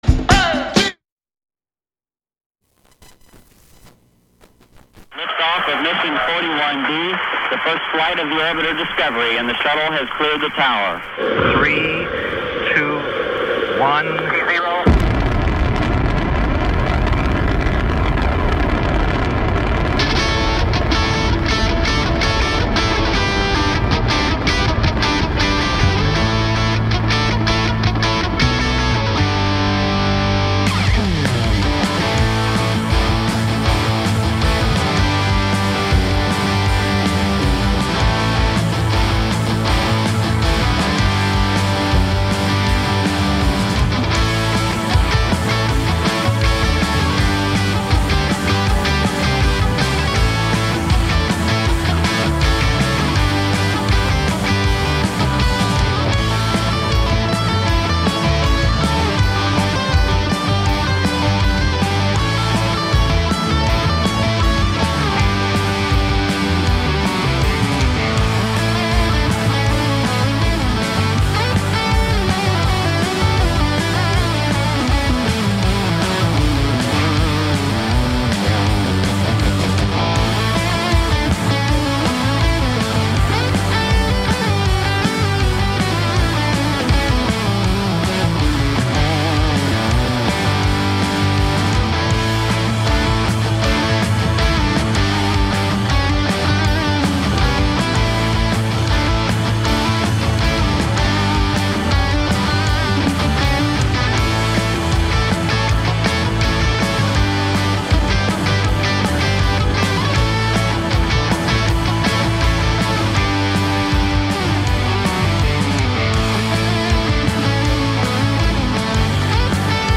Programa con la mejor musica rock